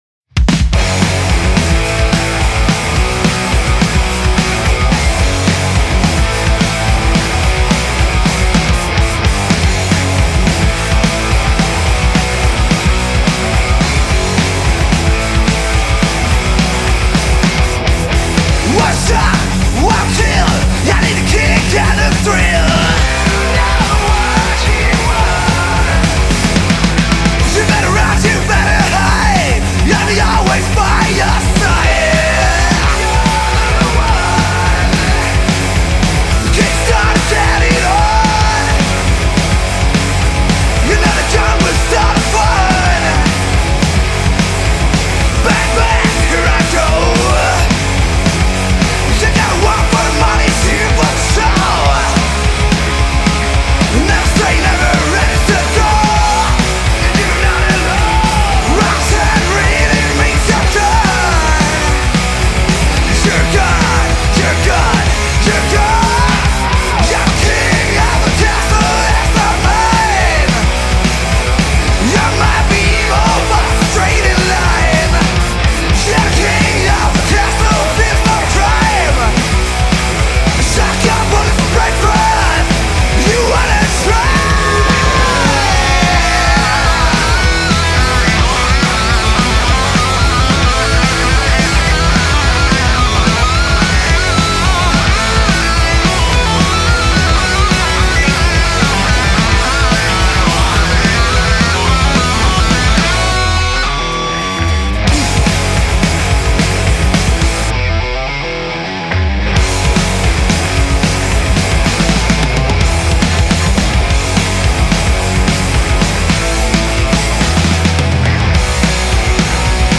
eine Rock-Band der alten Schule